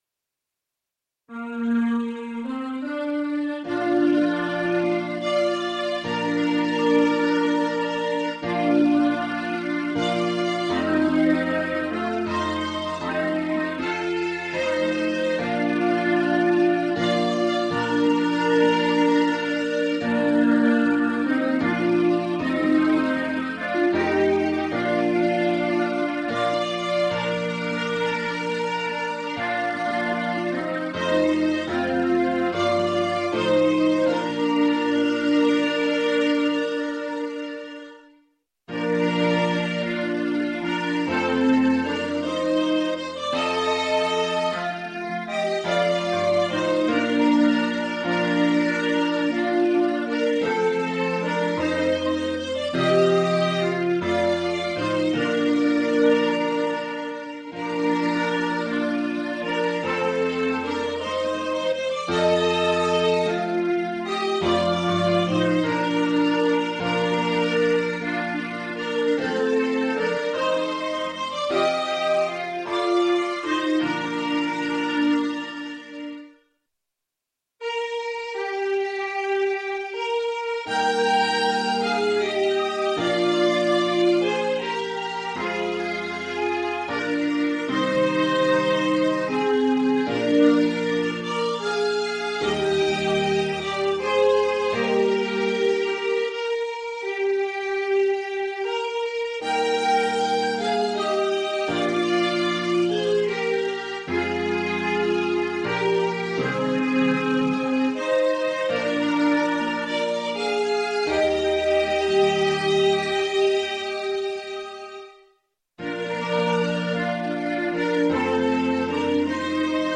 Naturjodel Komponist